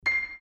пианино
короткие
Просто нотка